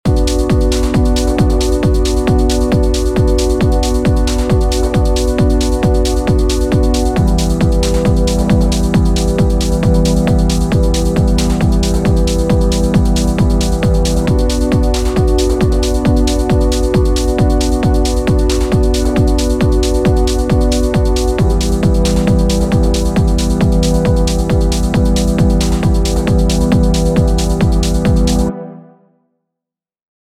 Load up Ableton’s Chord Pacific Pad and select the ‘No Chord’ preset. Choose two of the minor chords, drag them into the arrangement view and have them play four bars each – B minor and E minor for example.